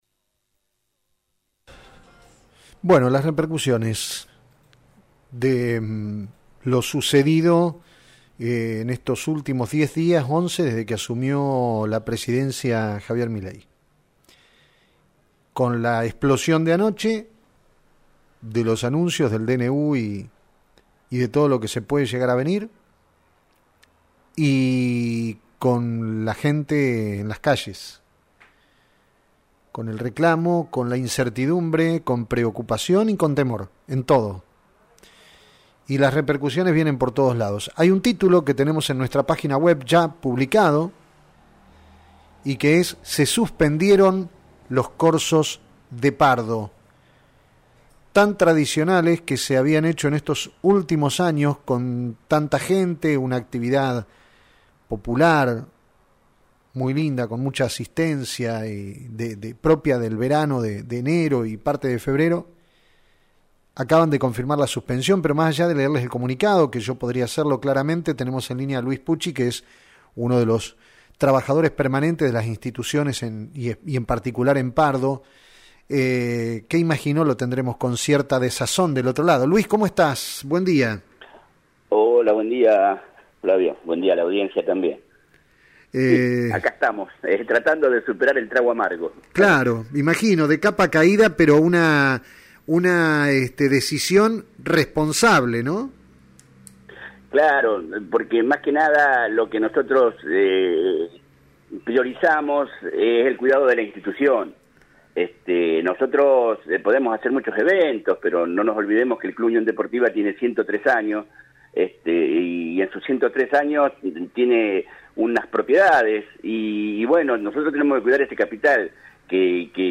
(entrevista a pié de nota)